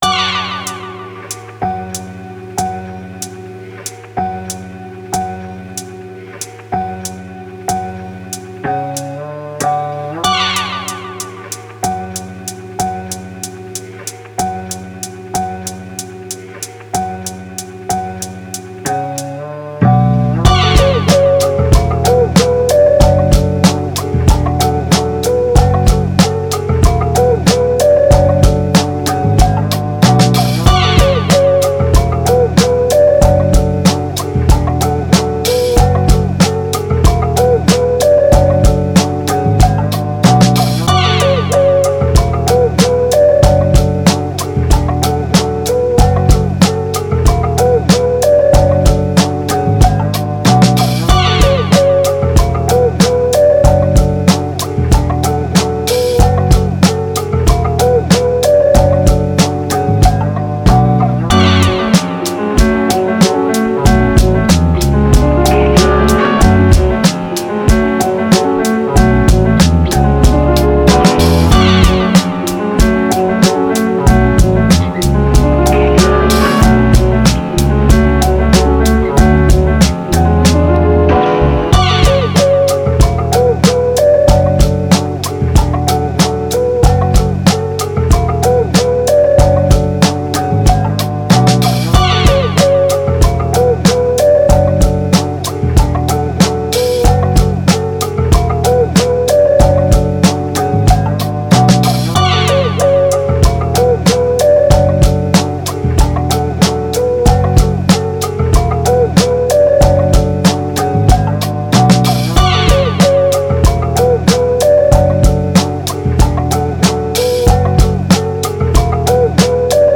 Hip Hop, 90s, Boom Bap, Aggressive, Fast